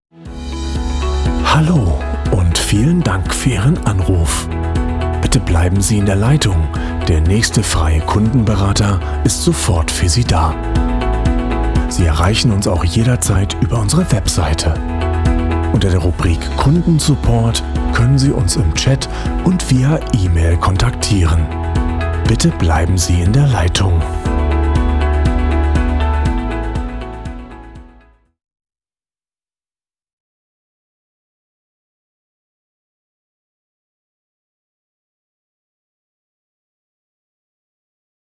Comercial, Profundo, Llamativo, Seguro, Cálida
Telefonía